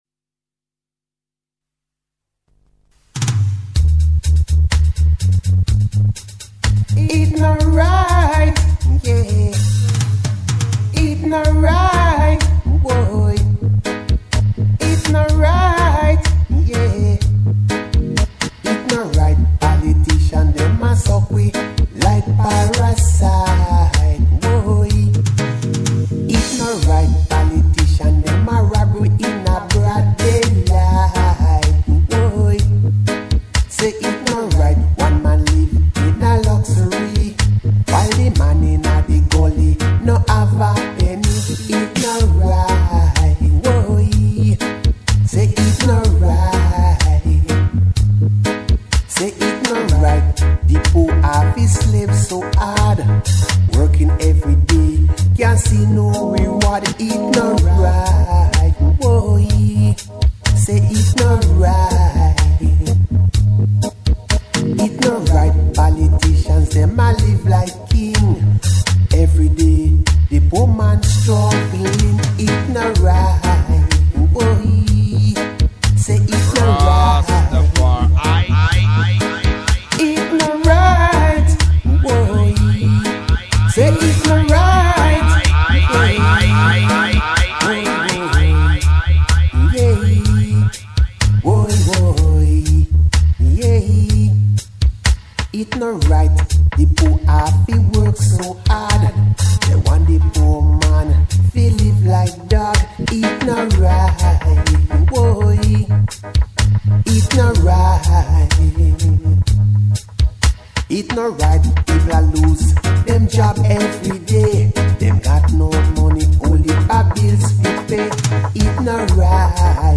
Enjoy...Rootsman Vibrations-some new & foundation oldies.